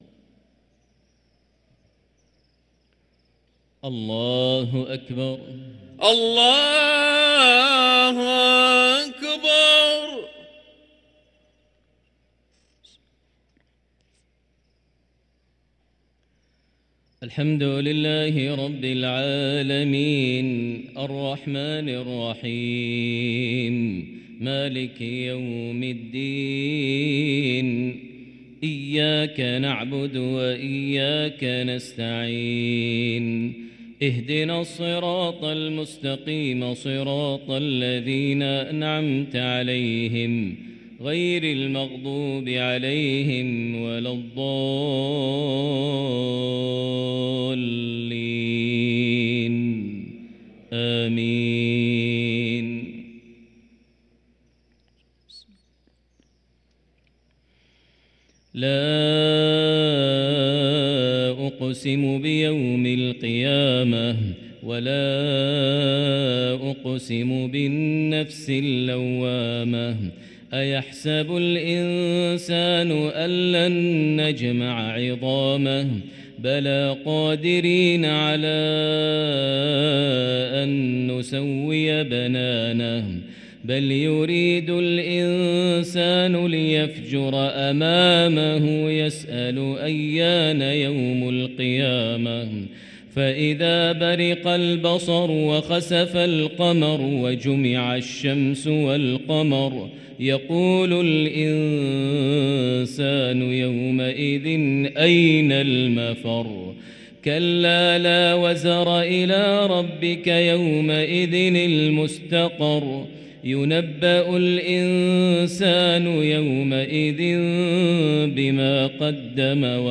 صلاة المغرب للقارئ ماهر المعيقلي 18 شعبان 1444 هـ
تِلَاوَات الْحَرَمَيْن .